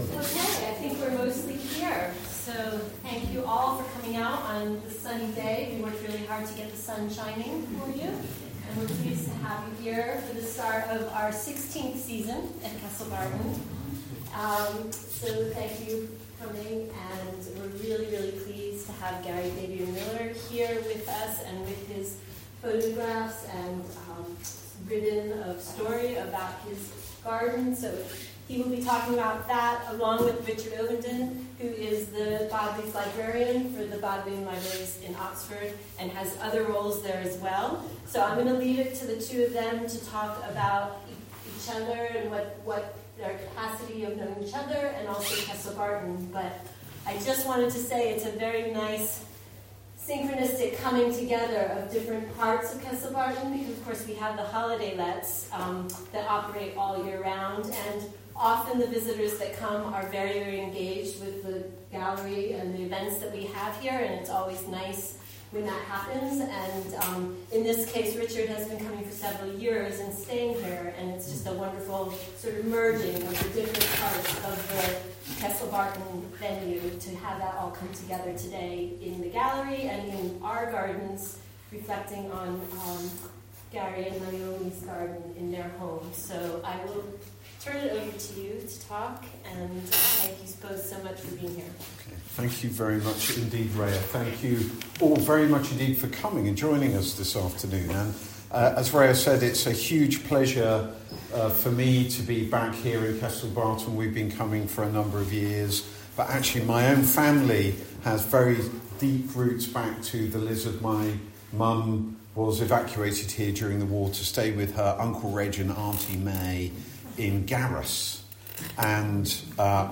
Artist Talk
The opening on Saturday 28 March from 2pm – 5pm included a conversation between Garry Fabian Miller and Richard Ovenden of the Bodleian Libraries at 3pm.
2026_GFM-Richard-Ovenden-Opening-talk.mp3